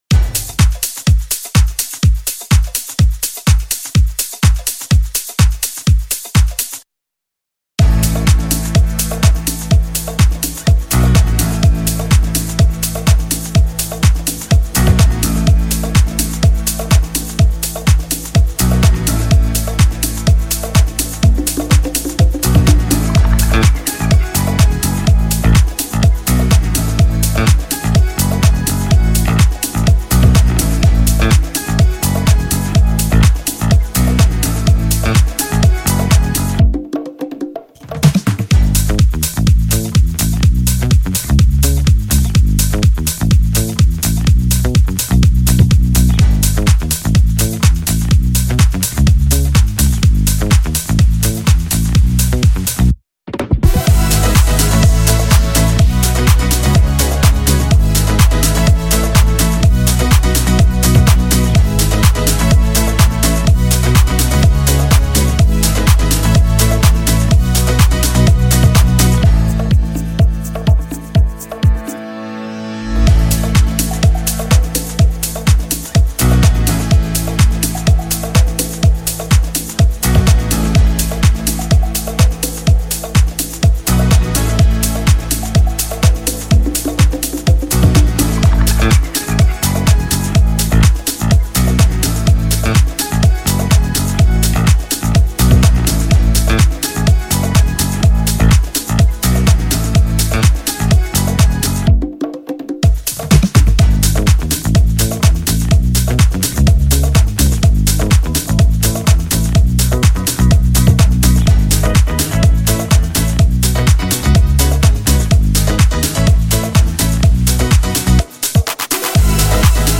Pop Instrumentals